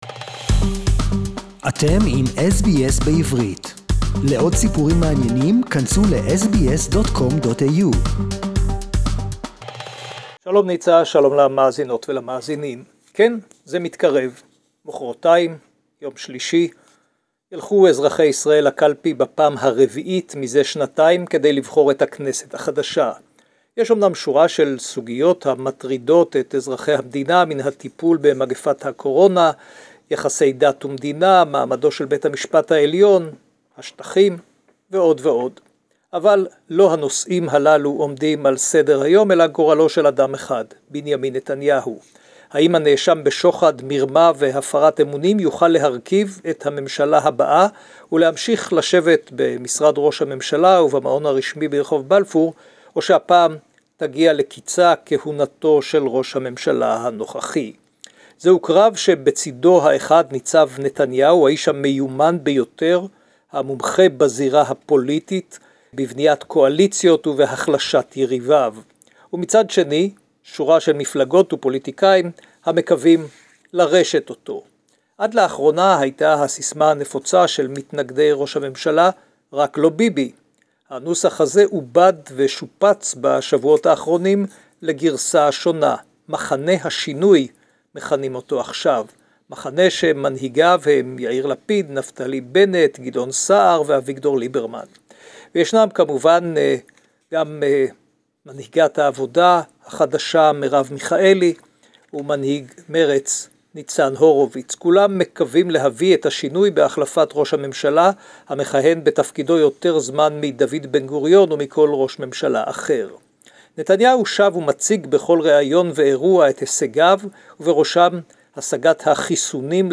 Israelis will head to the polls next week for the fourth parliamentary elections in two years. SBS Jerusalem report in Hebrew